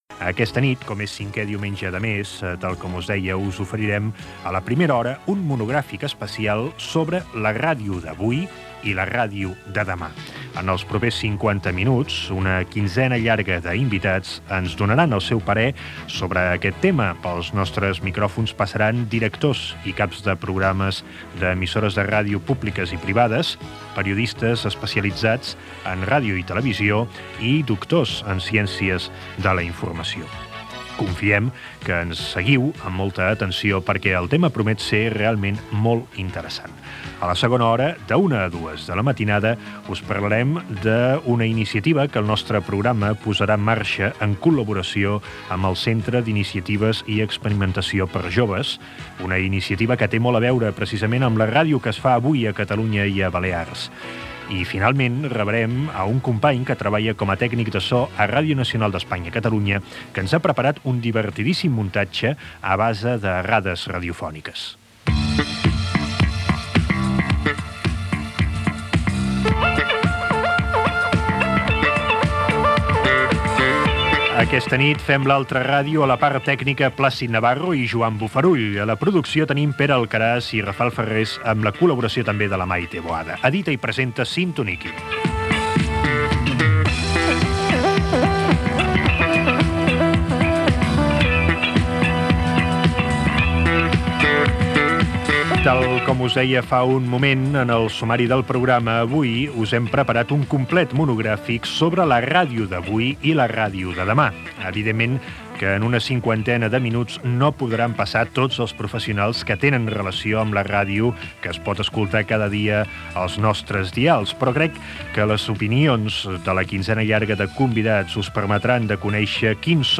Gènere radiofònic Divulgació